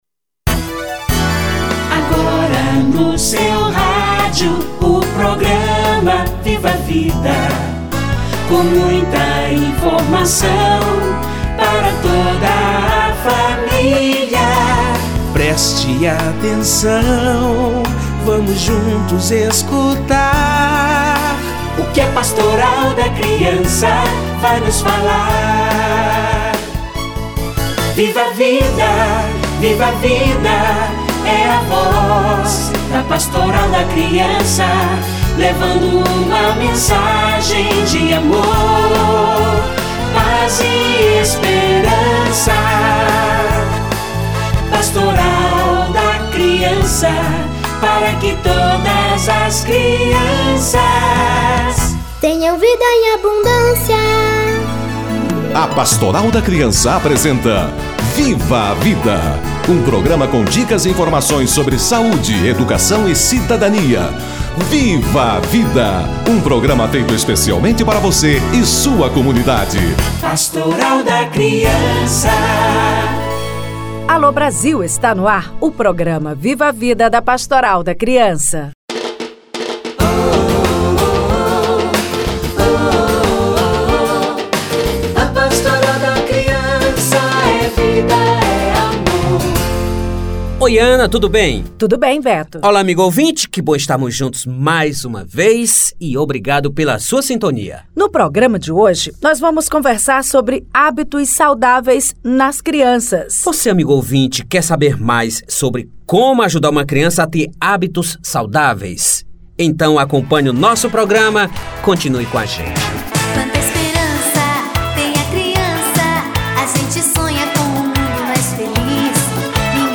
Rotinas e hábitos - Entrevista